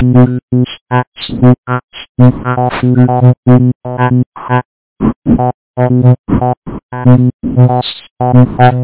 This probably has to be the worst speech synth of all time! It's called JS1k (JavaScript 1 KB), it's web-based, formant synthesis method, completely monotone, sample rate is 8 kHz, has very rough phoneme transitions, a terrible formant filter, and is entirely phonetic (i.e. you have to enter the phonemes in, so it's not doing actual standard English text-to-speech conversion).